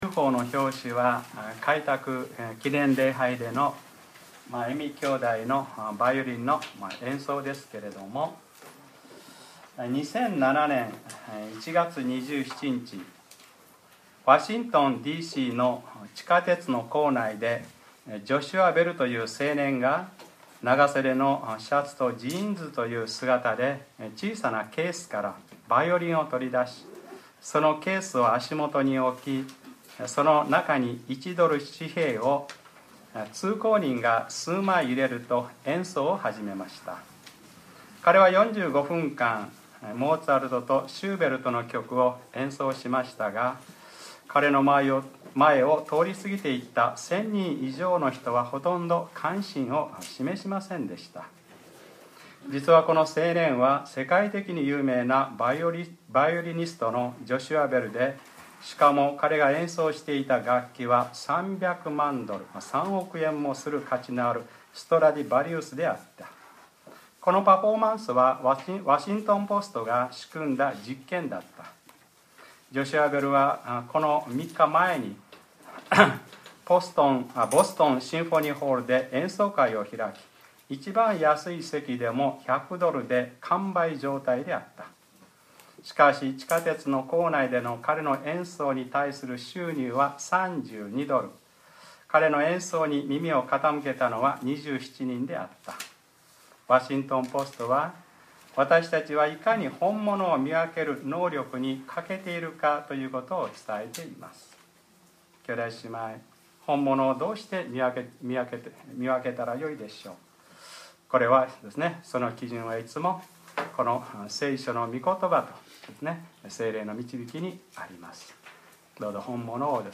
2013年10月20日(日）礼拝説教 『ルカｰ２７ 神があなたにどんなに大きなことを』